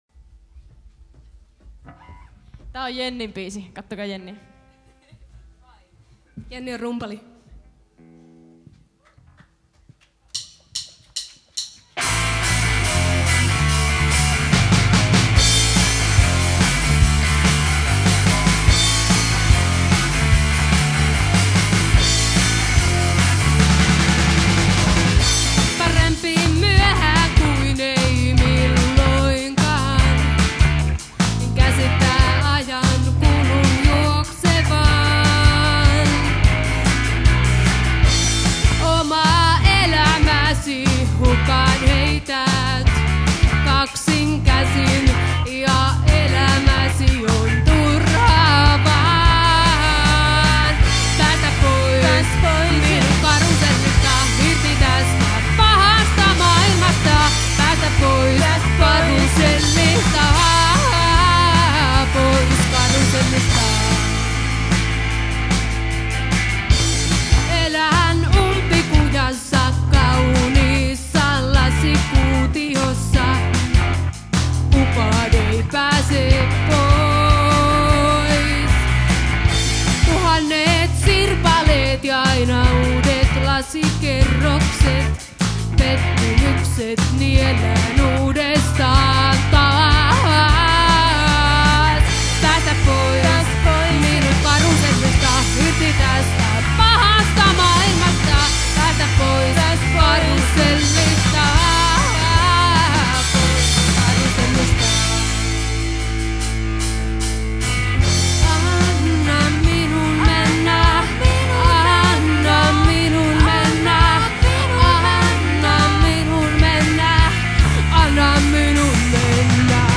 Liveäänitykset